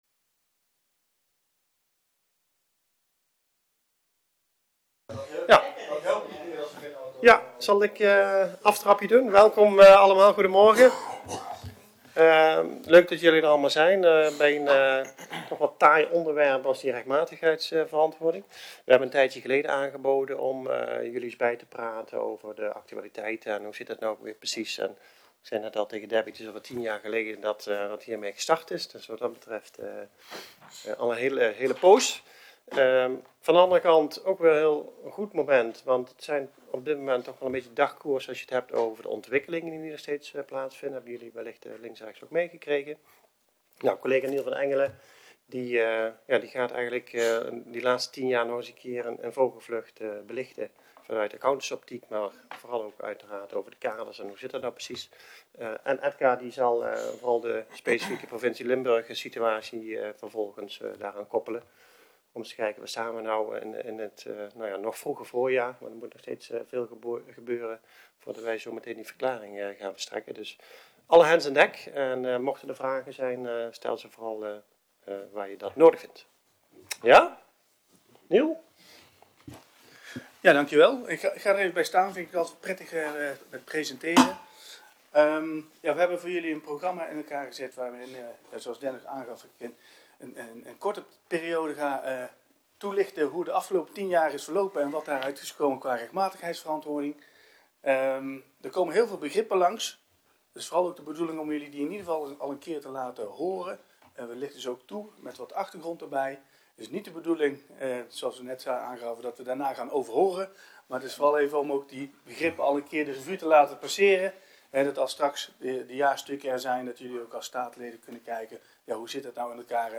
In deze interactieve informatiesessie staan de belangrijkste wijzigingen die met deze wetswijziging op uw af kunnen komen centraal.
Tijdens de informatiesessie is er alle ruimte om vragen c.q. ervaringen tot nu toe met elkaar te delen.
Locatie Maaszaal (D2.01) Toelichting In deze interactieve informatiesessie staan de belangrijkste wijzigingen die met deze wetswijziging op uw af kunnen komen centraal.